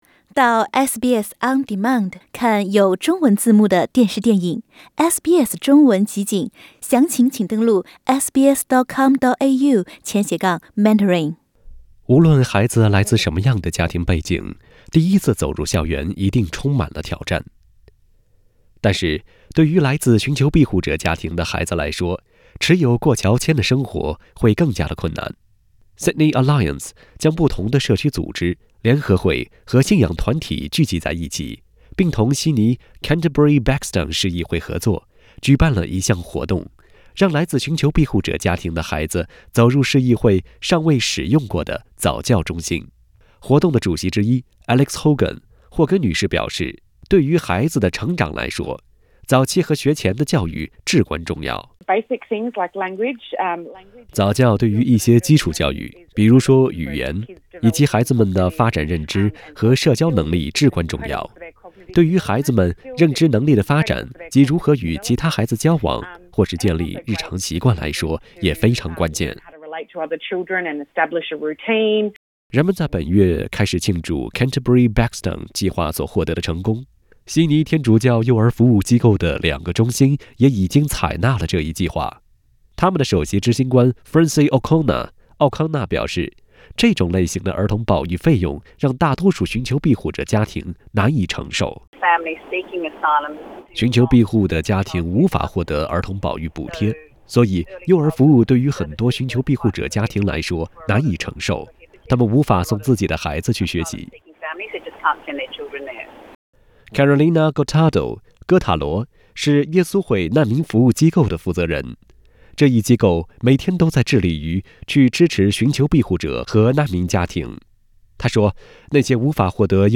Source: AAP SBS 普通話電台 View Podcast Series Follow and Subscribe Apple Podcasts YouTube Spotify Download (8.46MB) Download the SBS Audio app Available on iOS and Android 在澳洲持有過橋籤的尋求庇護者家庭在讓孩子接受早教等方面面臨著極大的問題。